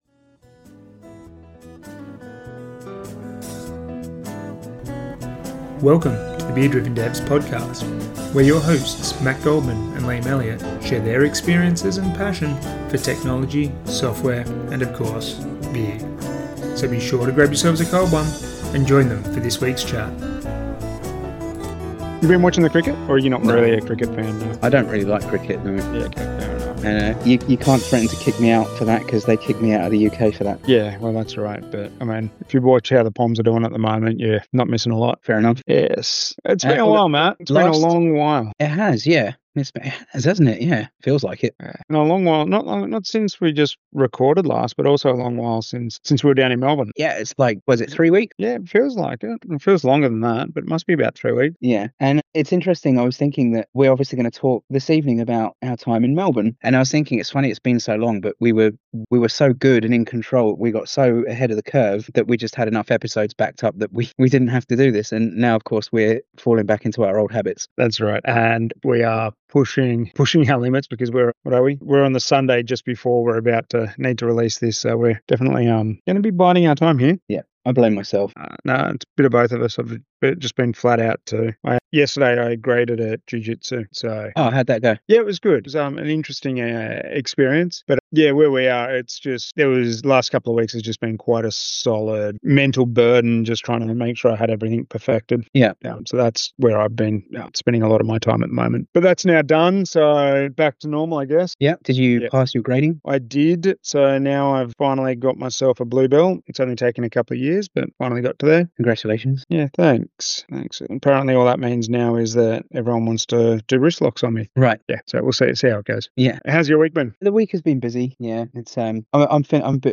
slightly croaky voices, slightly sleep-deprived